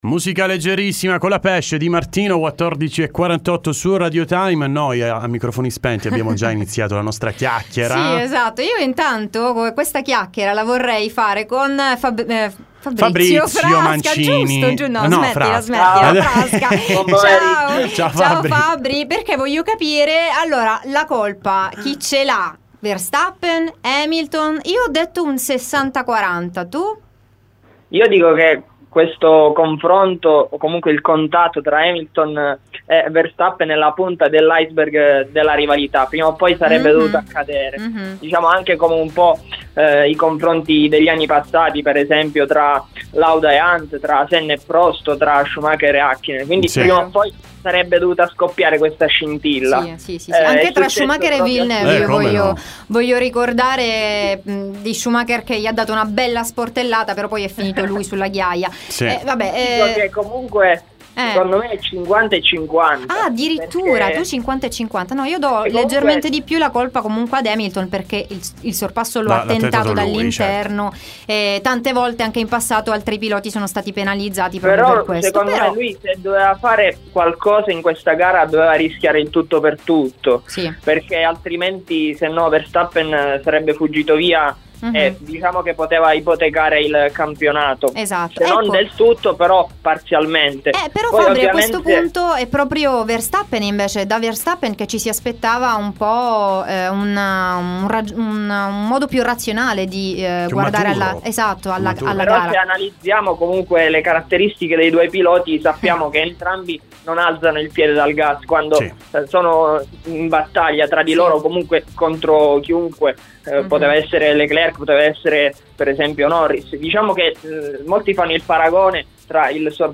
T.S. Intervista